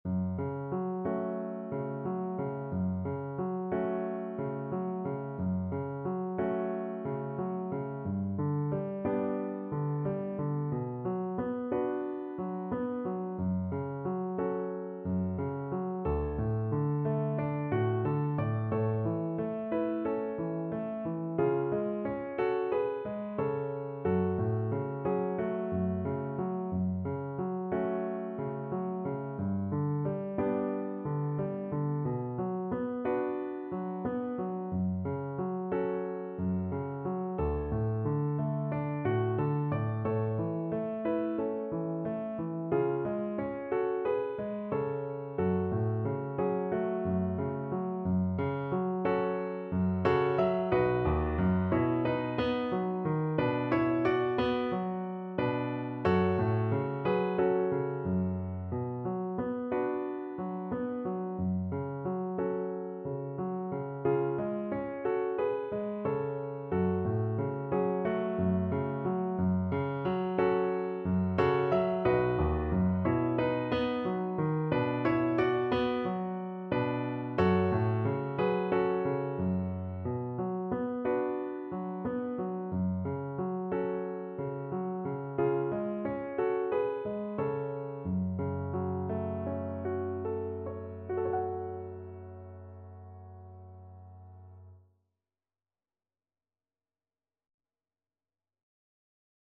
Gently Flowing =c.90
Classical (View more Classical Violin Music)